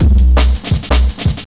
.wav 16 bit 44khz, Microsoft ADPCM compressed, mono,
Lmbrk7.wav Breakbeat 33k